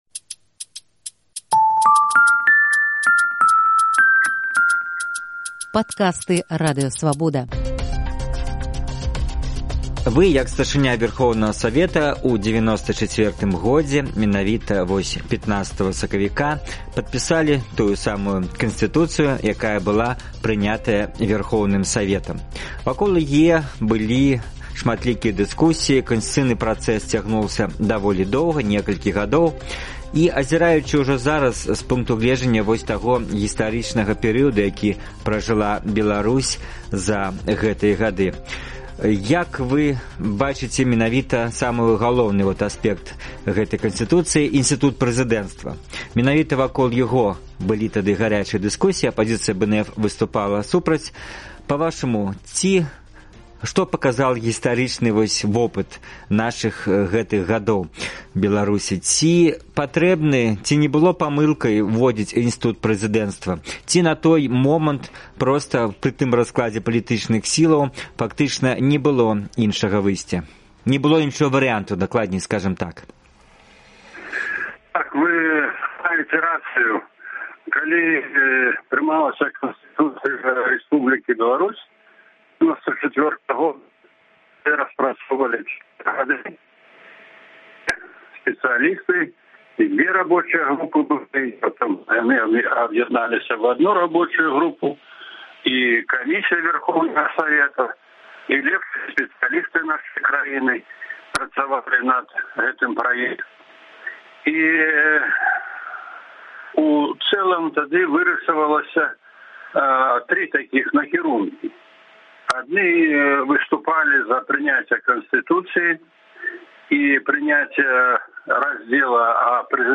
15 сакавіка 1994 году ў была прынята першая Канстытуцыя незалежнай Беларусі, подпіс пад якой паставіў тагачасны Старшыня Вярхоўнага Савету Мечаслаў Грыб. У “Інтэрвію тыдня” ён тлумачыць, чаму пасьля зьмены ўлады трэба будзе вярнуцца да Канстытуцыі ў рэдакцыі 1994 году.